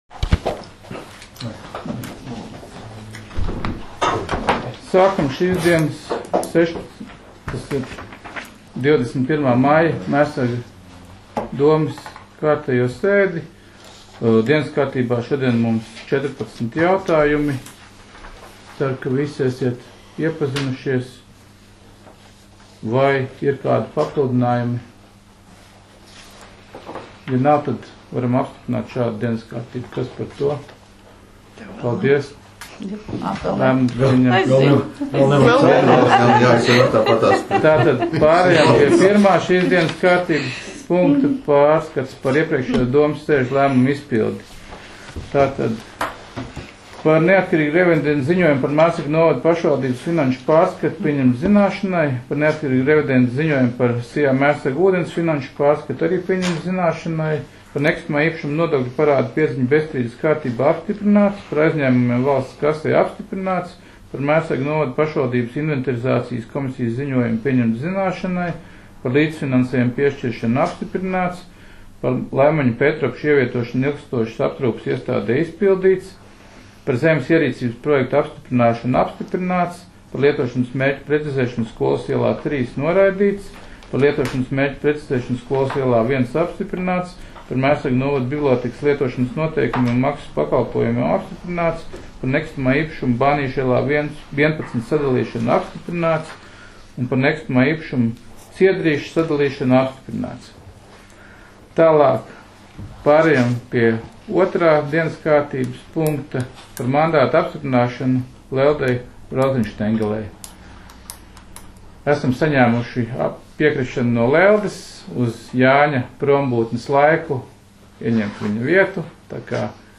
Mērsraga novada domes sēde 21.05.2019.